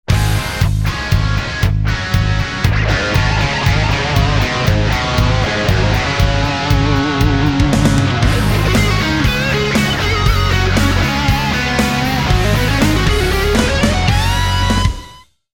GT2 BRITISH SOUND SAMPLE
Tech 21 SansAmp GT2 preamp
Yamaha Pacifica 120 and 112 guitars*
Gt2brit.mp3